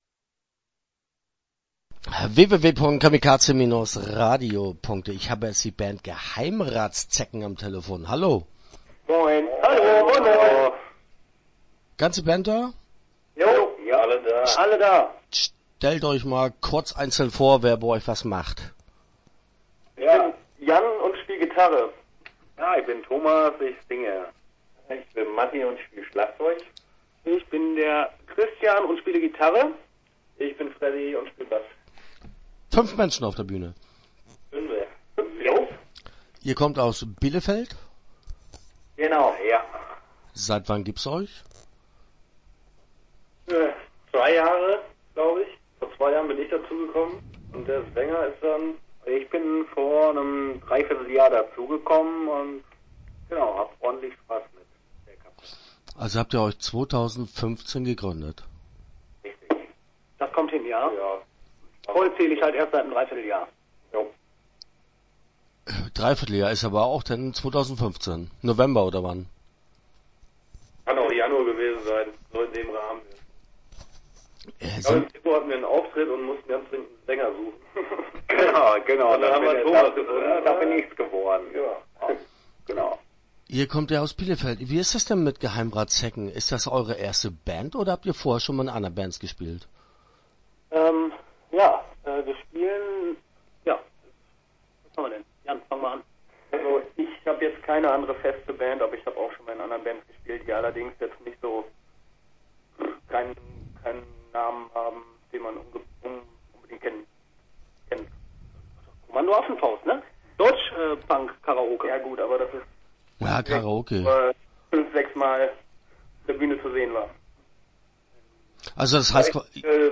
Start » Interviews » Geheimratszecken